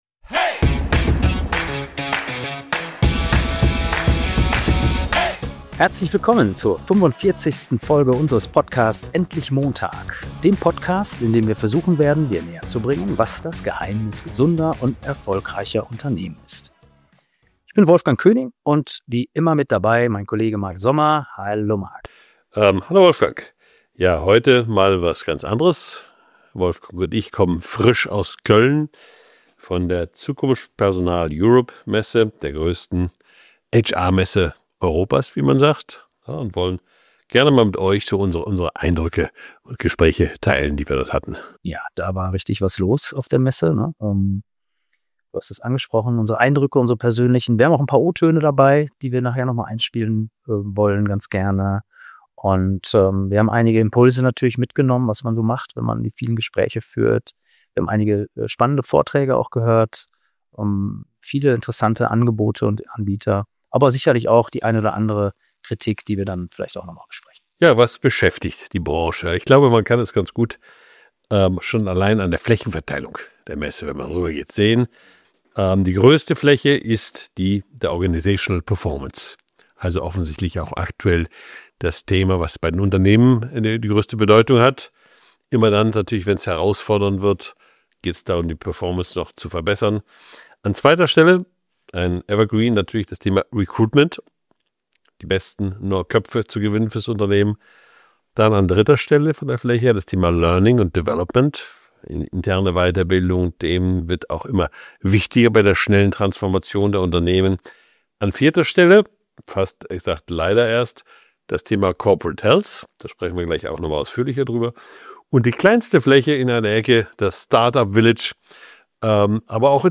In dieser Episode nehmen wir dich mit auf unseren Rundgang über die Messe Zukunft Personal in Köln. Wie viel Platz hat das Thema Gesundheit wirklich auf so einem Event?